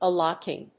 /ὰːləkíŋ(米国英語)/